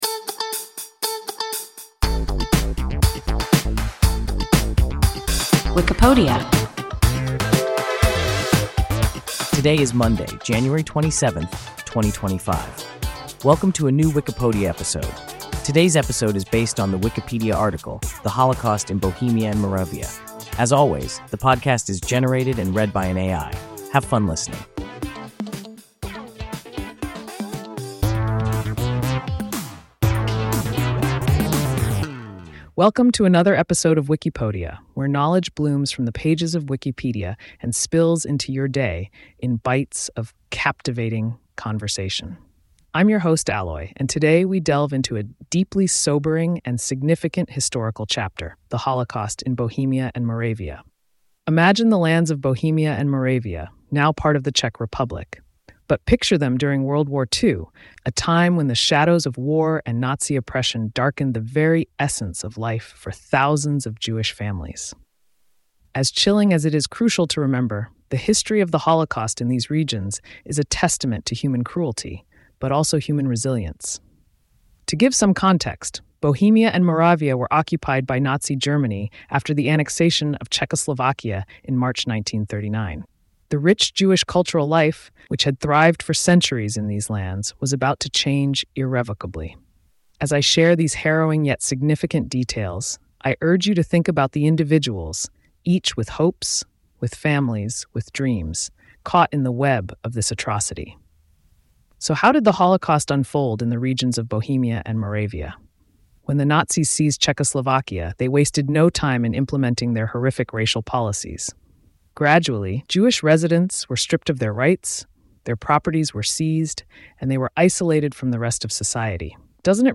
The Holocaust in Bohemia and Moravia – WIKIPODIA – ein KI Podcast